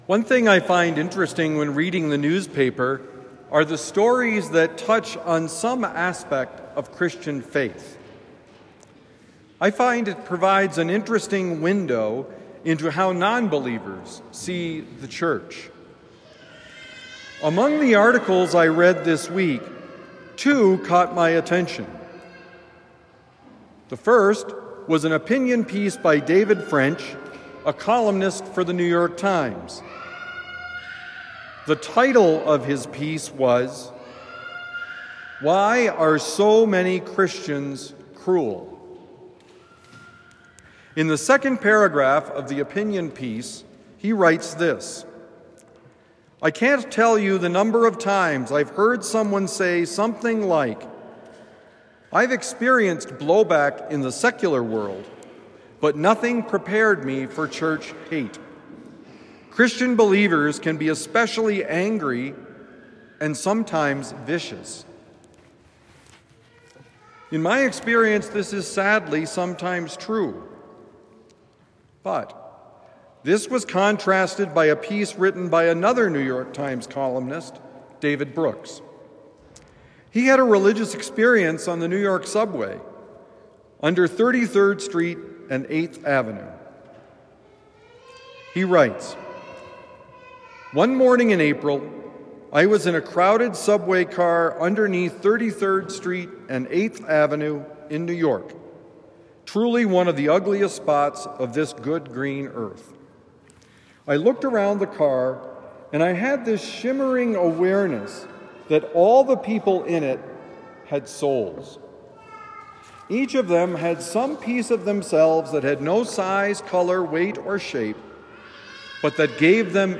Becoming a holy family: Homily for Sunday, December 29, 2024